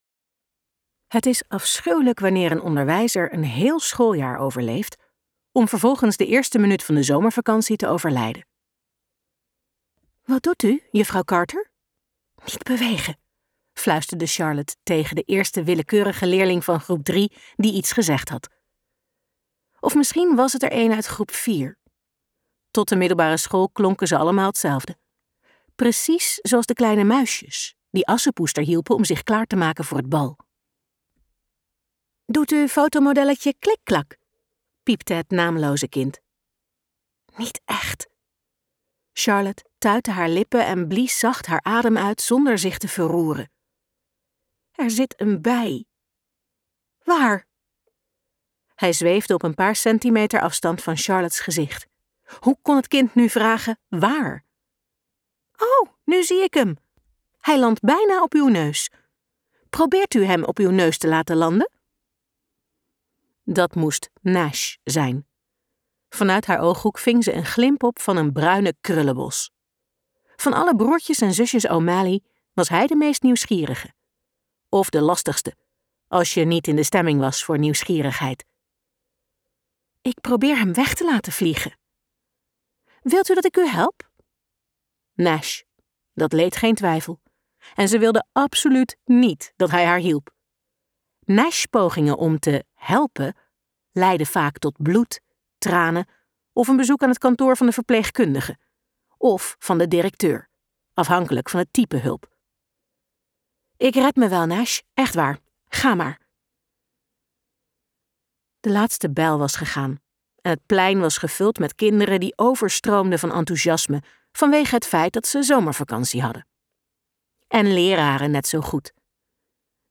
KokBoekencentrum | Liefde op een tandem luisterboek